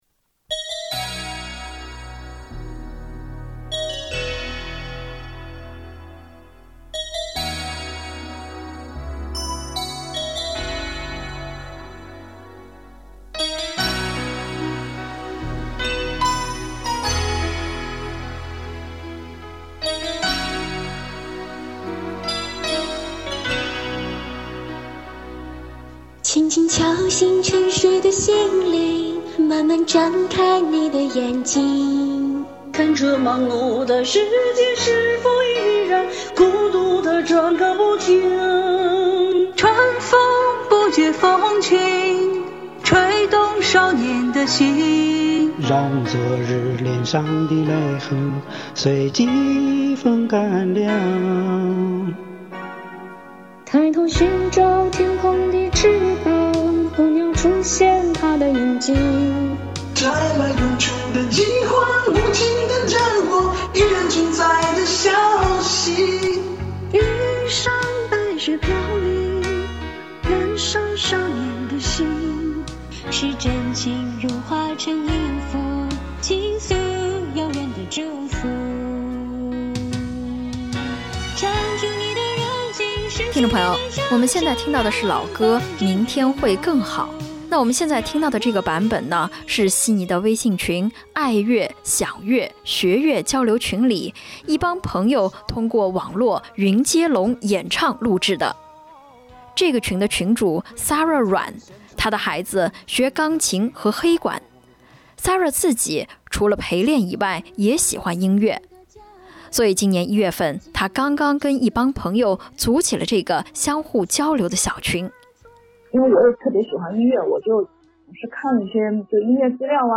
采访开头的老歌《明天会更好》由悉尼微信群【爱乐享乐学乐交流群】里的一帮朋友通过网络云接龙演唱、录制、合成。